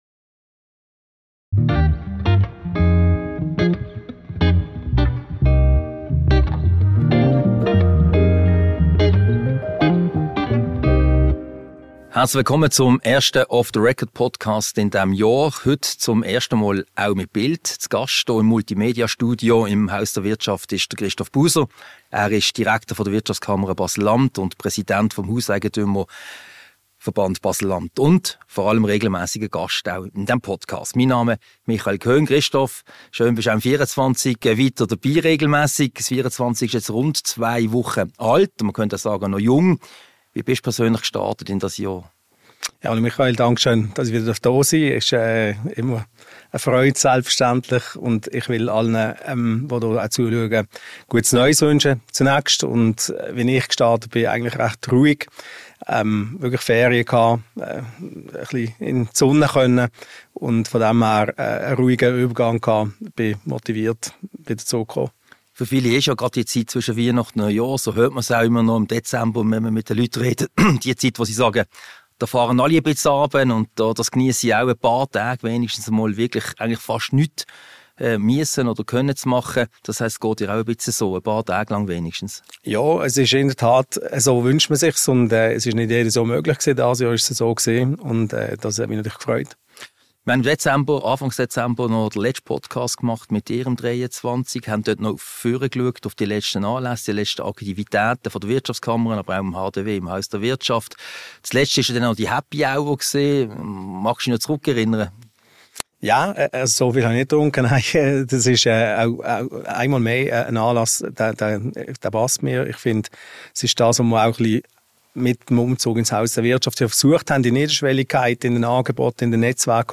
Ein Gespräch über das Jahr 2024 und die aktuellen Herausforderungen für Wirtschaft und Politik.